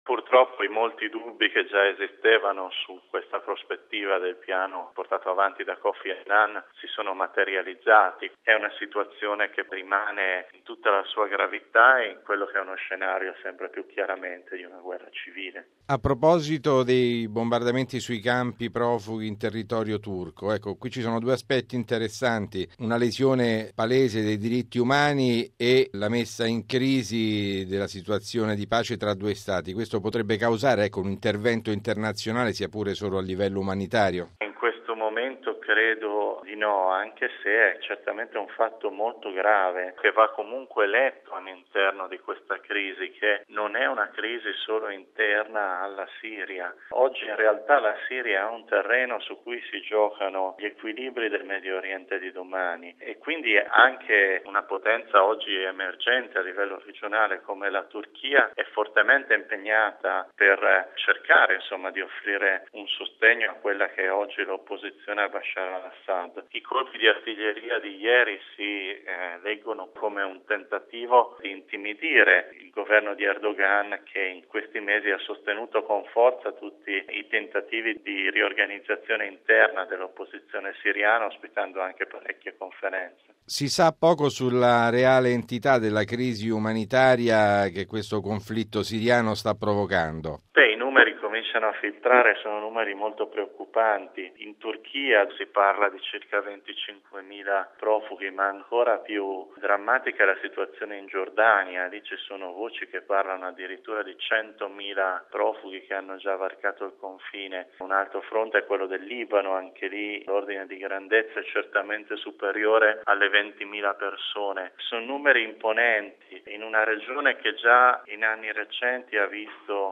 esperto di Medio Oriente